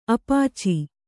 ♪ apāci